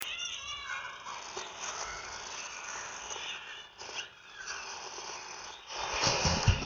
delfines.mp3